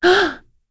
Lorraine-Vox-Sur3.wav